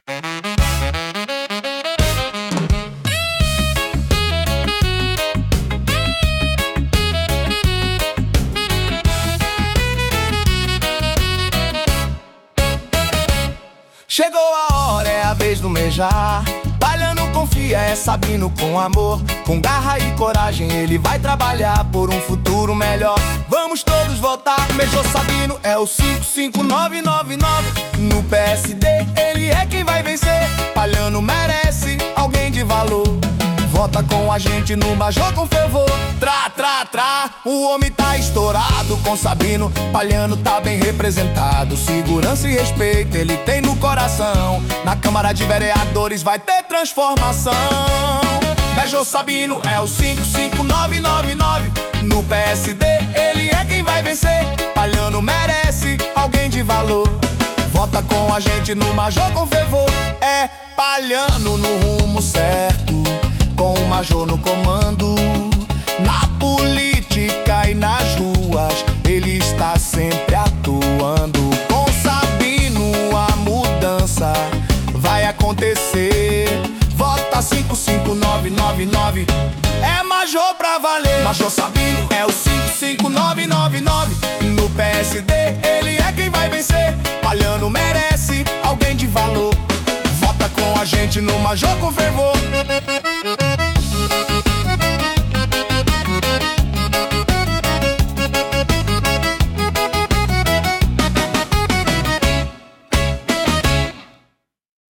Versão Reggae 1